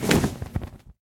Minecraft Version Minecraft Version 1.21.5 Latest Release | Latest Snapshot 1.21.5 / assets / minecraft / sounds / mob / enderdragon / wings4.ogg Compare With Compare With Latest Release | Latest Snapshot
wings4.ogg